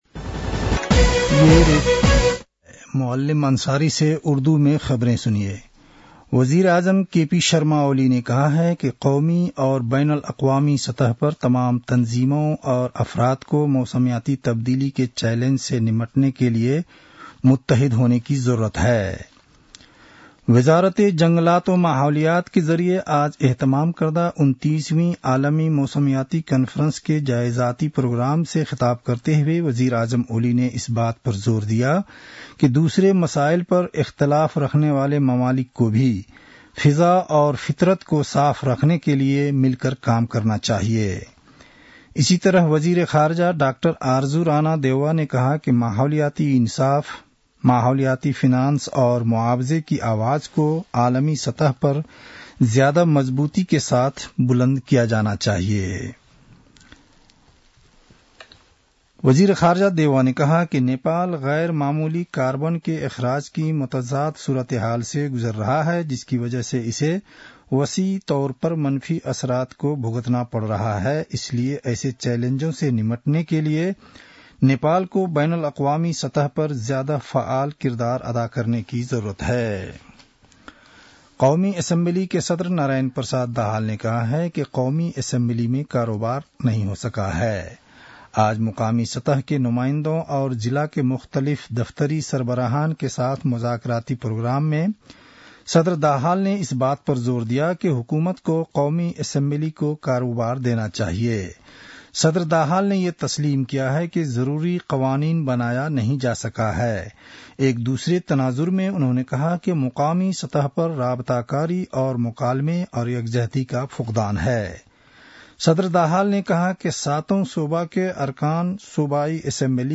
उर्दु भाषामा समाचार : १२ पुष , २०८१